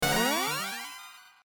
Download Free Win Sound Effects